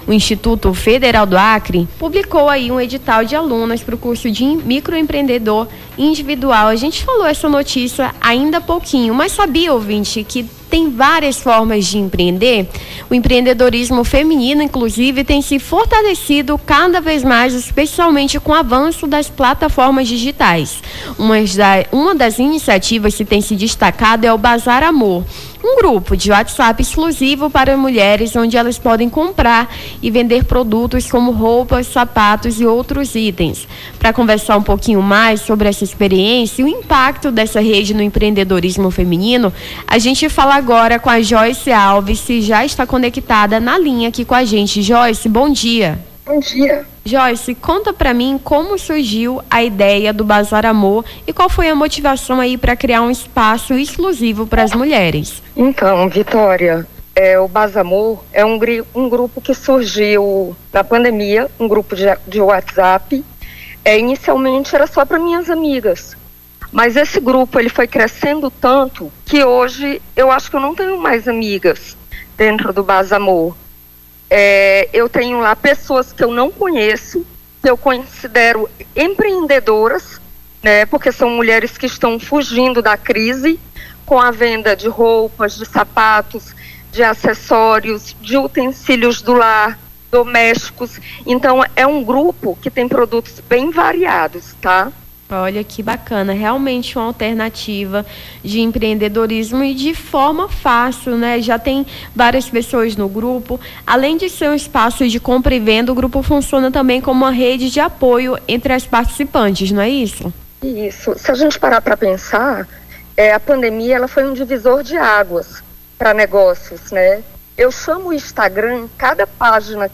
Nome do Artista - CENSURA - ENTREVISTA EMPREENDEDORISMO FEMININO (14-02-25).mp3